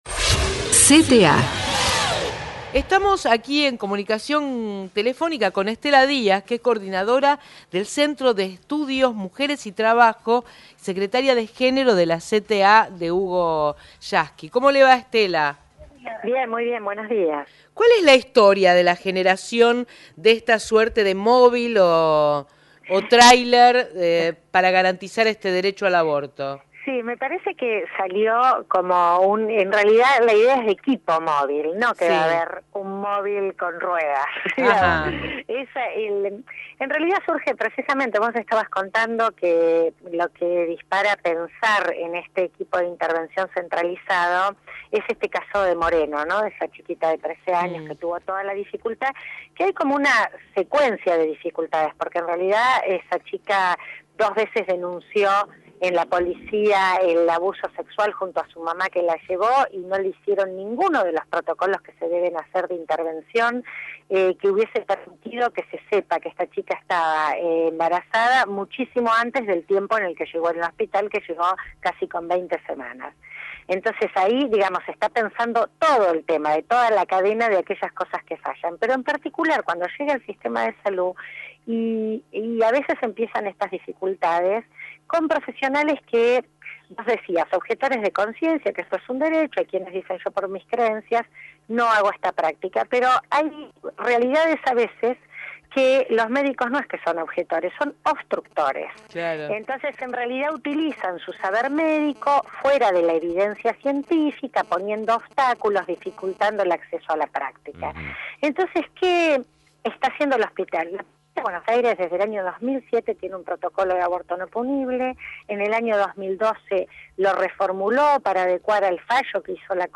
La secretaria de Género de la CTA entrevistada por Miriam Lewin en el programa "Mañana es hoy" de la Radio Pública